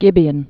(gĭbē-ən)